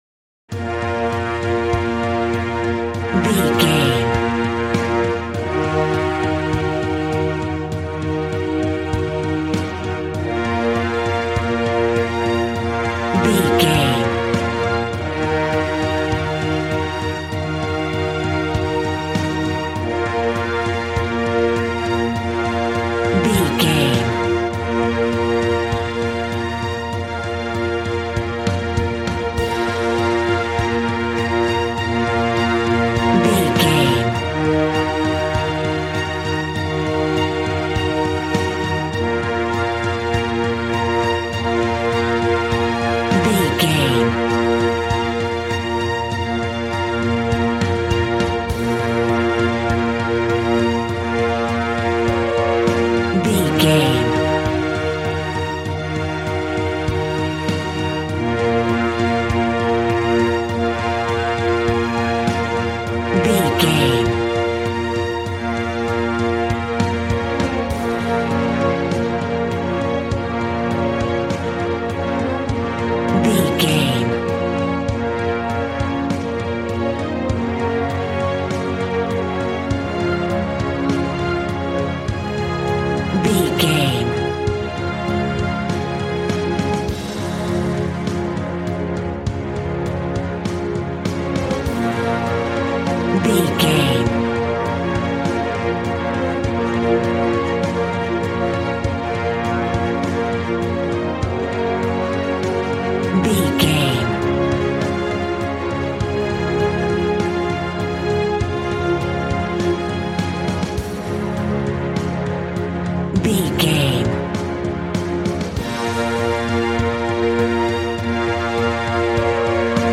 Aeolian/Minor
B♭
dramatic
epic
strings
violin
brass